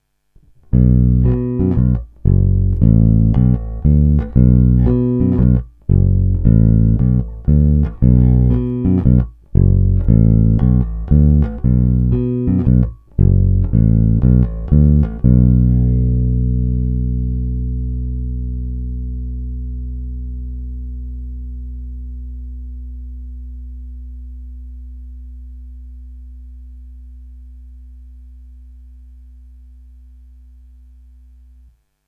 Zvonivý, vrnivý, pevný.
Není-li uvedeno jinak, následující nahrávky jsou provedeny rovnou do zvukovky a kromě normalizace ponechány bez dodatečných úprav.
Hráno mezi krkem a snímačem